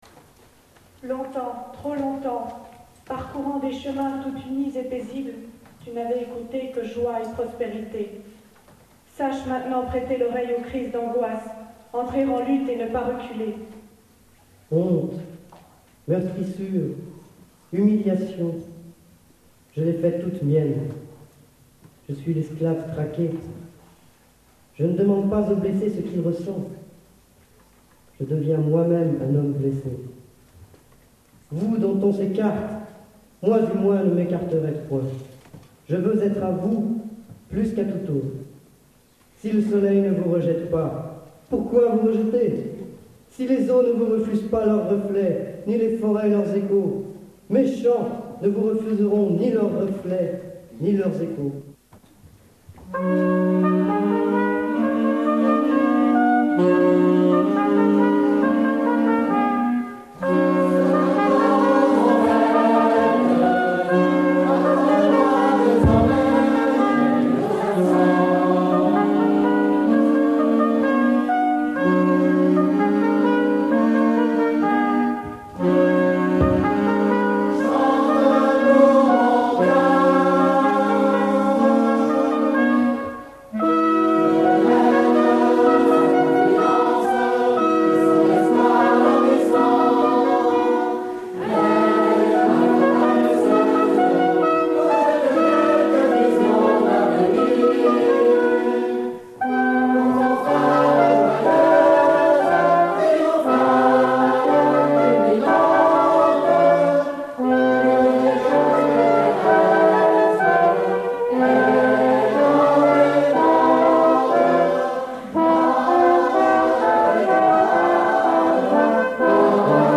Concert "A Coeur Joie" 1 Juin 1979 CLUNY avec la Chorale "Clunysia",
l'Ensemble Vocal Mixte et l'Ensemble Vocal de l'ENSAM
Extaits du Concert de CLUNY